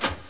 CLUNK1.WAV